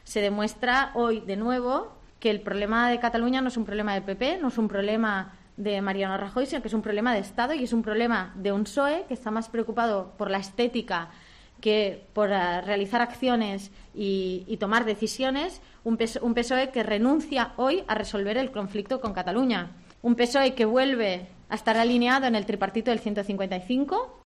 La portavoz del Govern ha comparecido en una rueda de prensa en el Palau de la Generalitat tras el anuncio del adelanto electoral del 28 de abril, que ha achacado al "fracaso y la falta de coraje" de Sánchez, que le ha llevado, a su juicio, a "malbaratar" la moción de censura y a "ceder" a las presiones de PP, Ciudadanos y Vox.